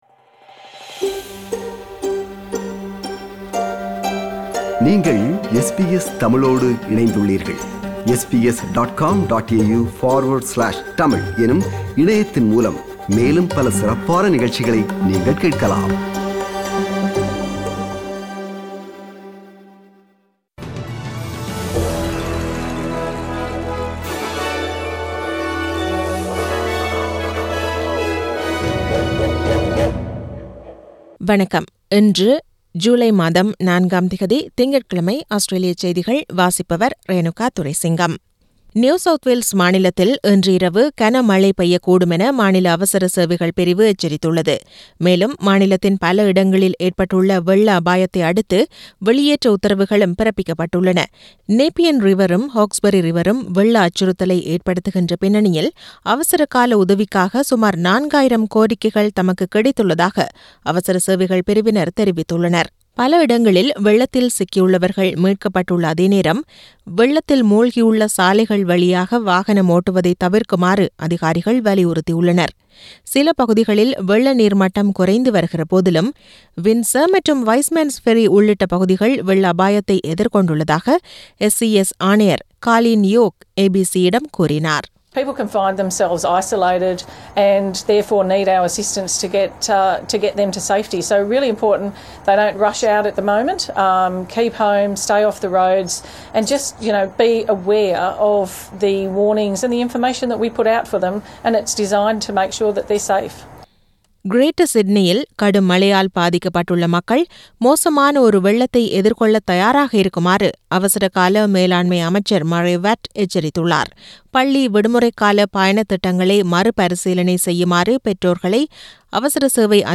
Australian news bulletin for Monday 04 July 2022.